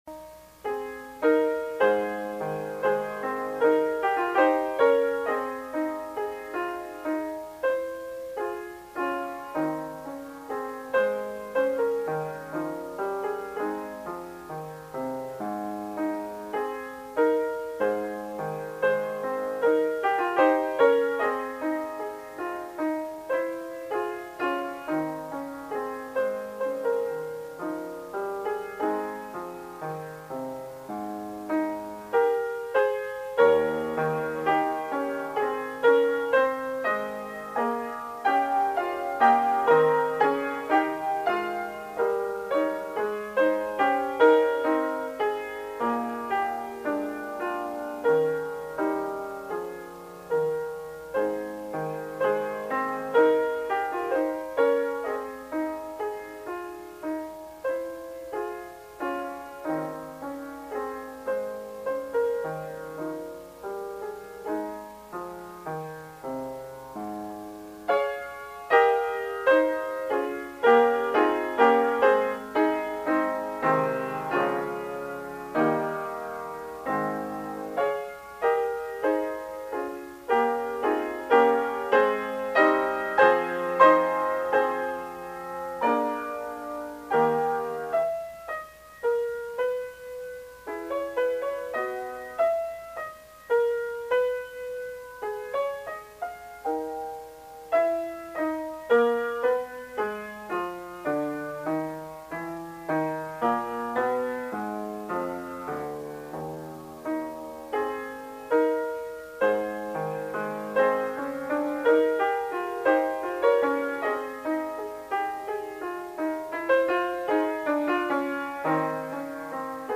Splendid piano arrangement
Raptuous melody and harmony.